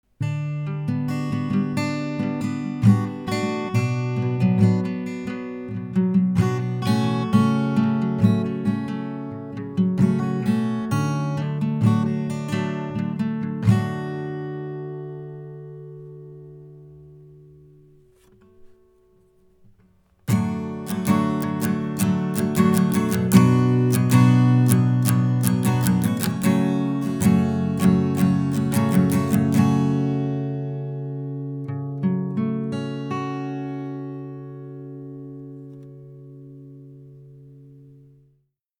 In the test I recorded voice and acoustic guitar in different card modes.
Lewitt Connect 6 sound card + Lewitt 240 Pro microphone:
🎸 Guitar recording:
As you can hear from the examples, the sound is clear, there is no noise or distortion.
lewitt-240-pro-guitar.mp3